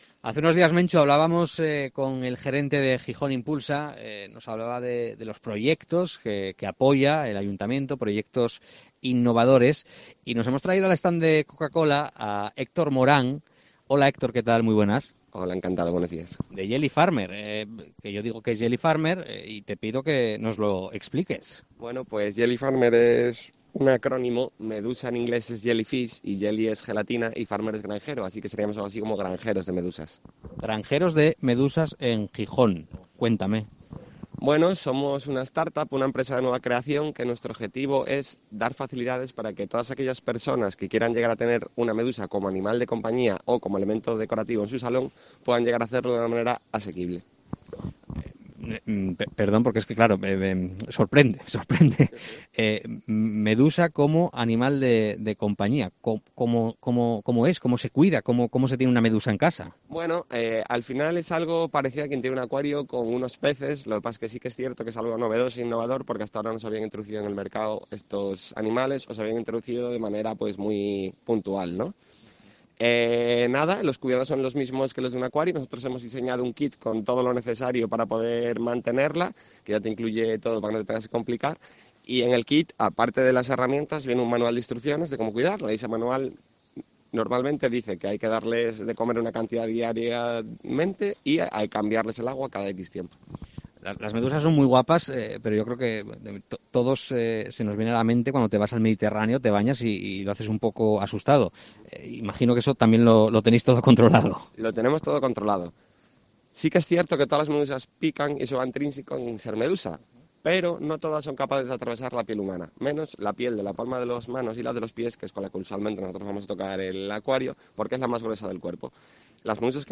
Entrevista
en la Feria de Muestras